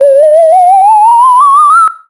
BONUS 2 - SFX
POWERUP 1.wav